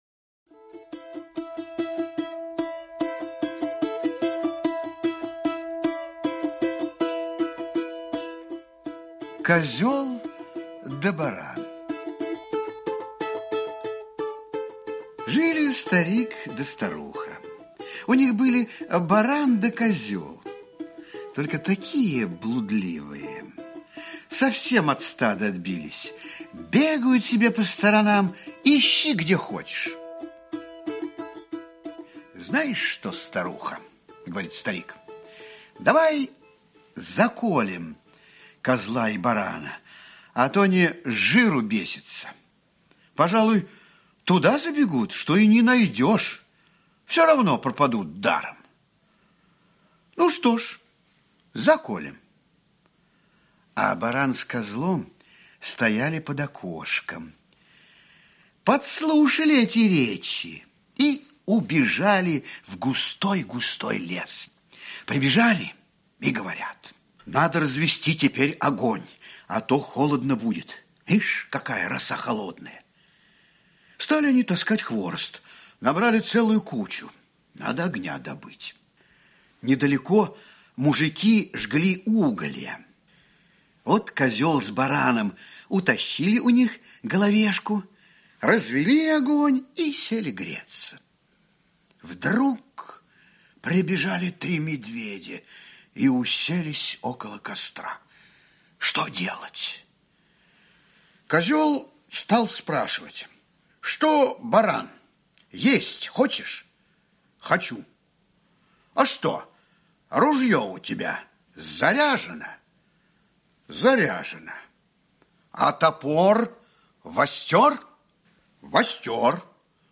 Аудиокнига Русские народные сказки | Библиотека аудиокниг
Прослушать и бесплатно скачать фрагмент аудиокниги